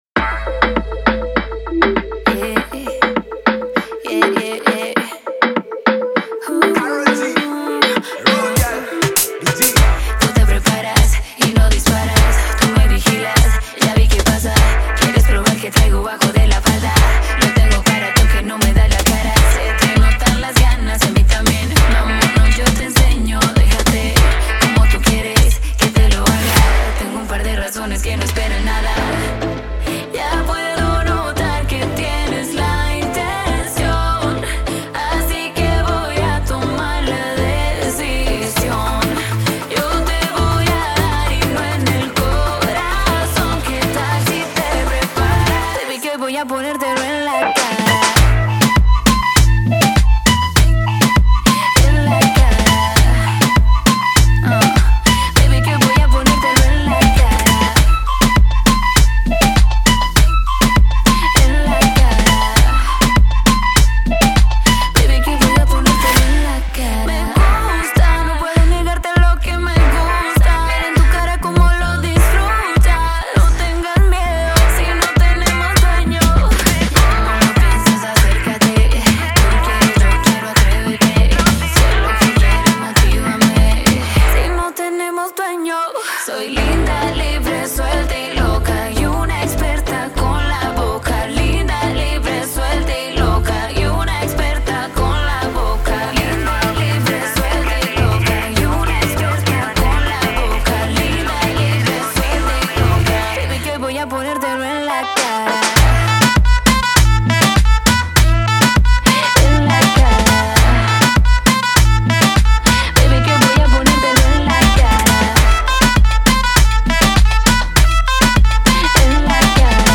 это зажигательный трек в жанре реггетон и EDM